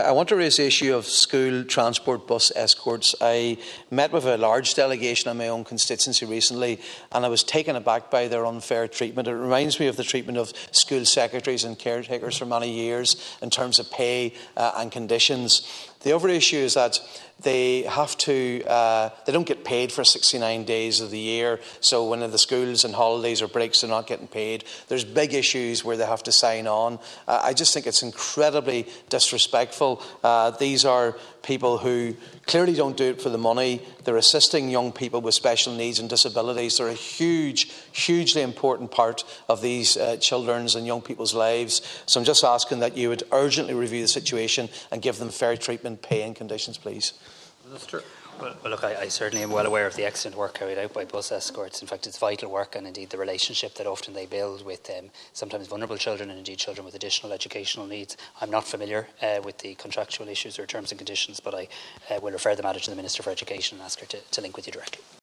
Donegal Deputy Padraig MacLochlainn told the Dail that these workers escort children with special needs and disabilities to and from school every day on the bus, but their pay and conditions do not reflect the importance of their work.